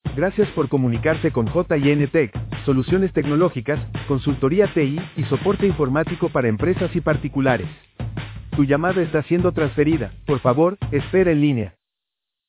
A continuación un ejemplo de IVR de bienvenida…
jntech-hola.wav